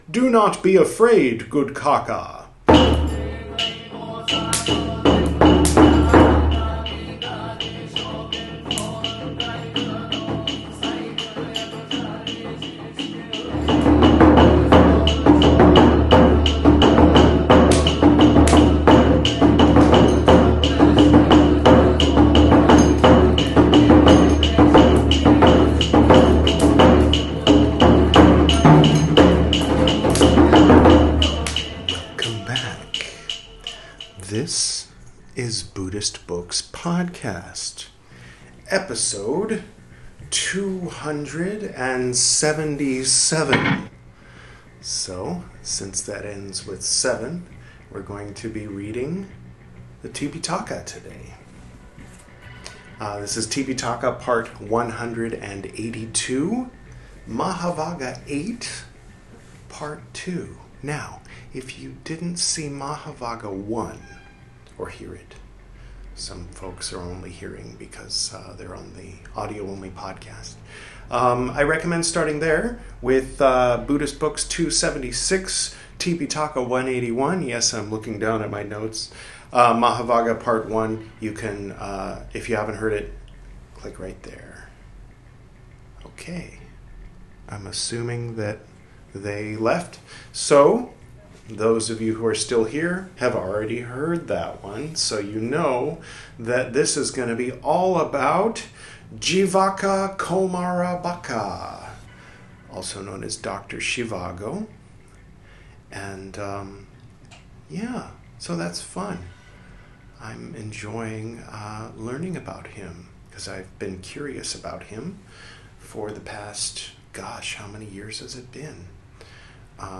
This is Part 182 of my recital of the 'Tipiṭaka,' the 'Three Baskets' of pre-sectarian Buddhism, as translated into English from the original Pali Language. In this episode, we'll continue reading 'Mahāvagga VIII,' from the 'Vinaya Piṭaka,' the first of the three 'Piṭaka,' or 'Baskets.'